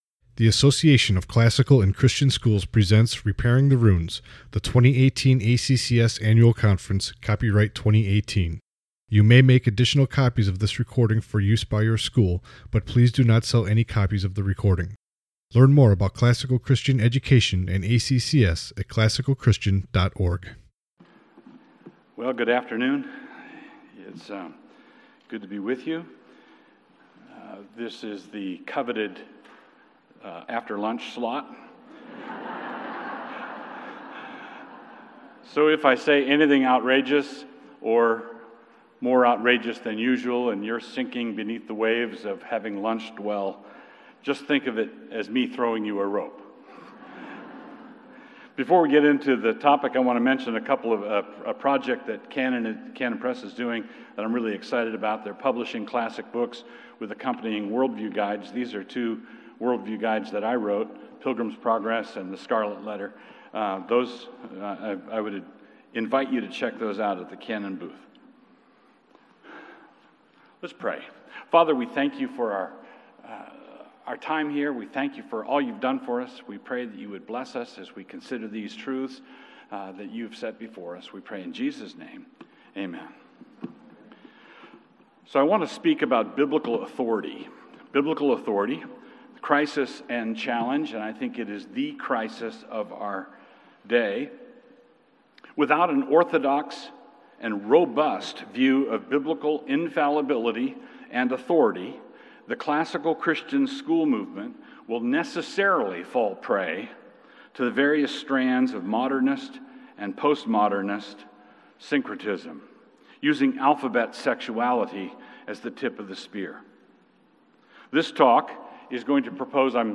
2018 Plenary Talk | 51:59 | All Grade Levels, Bible & Theology, General Classroom
Additional Materials The Association of Classical & Christian Schools presents Repairing the Ruins, the ACCS annual conference, copyright ACCS.